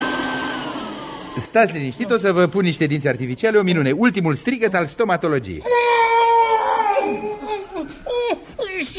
stati-linistit-o-sa-va-pun-niste-dinti-artificiali-o-minune-ultimul-strigat-al-stomatologiei-tipa-de-durere.mp3